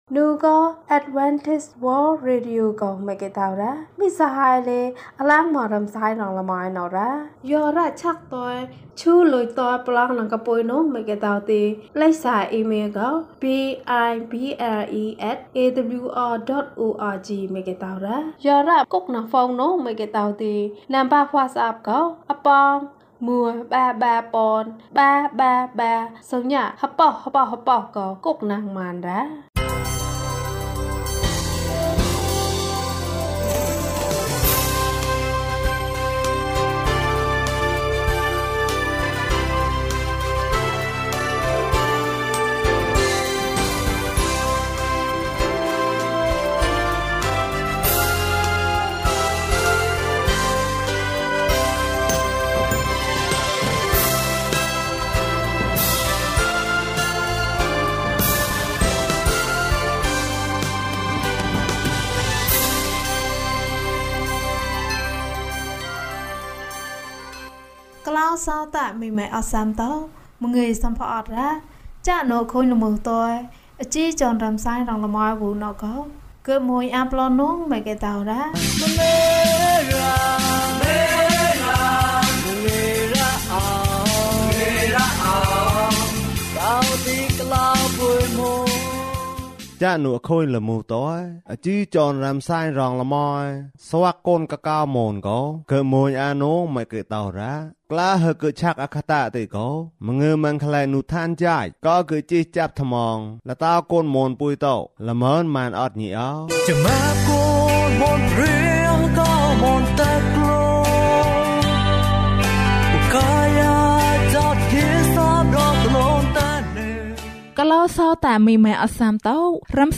ငါဘုရားသခင့်စကားများမျှဝေမည်။ ကျန်းမာခြင်းအကြောင်းအရာ။ ဓမ္မသီချင်း။ တရားဒေသနာ။